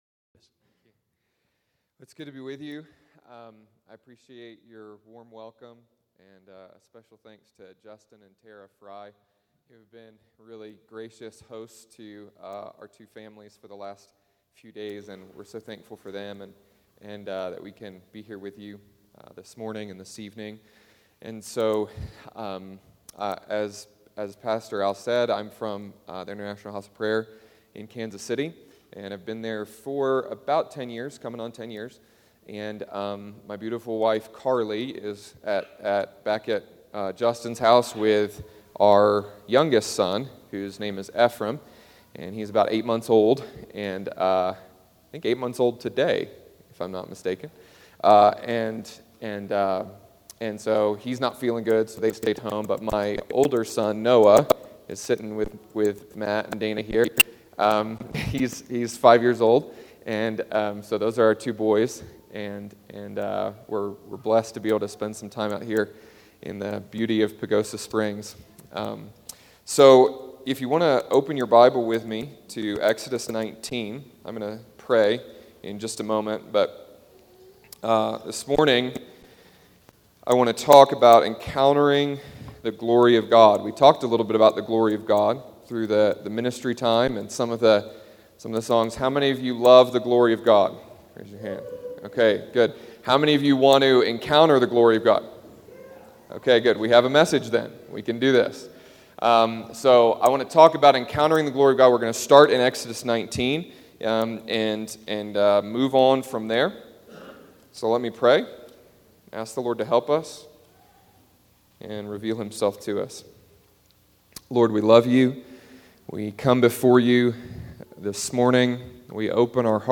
Beginning with the drama of Israel’s experience with God at Mt. Sinai, this message explores how our desire to encounter the glory of God is fulfilled in the majesty of the person of Christ. We are invited to leave the mystery of Jesus untamed and be drawn into breathtaking reality of the Incarnation. Spoken on June 3rd, 2012 at Restoration Fellowship in Pagosa Springs, CO.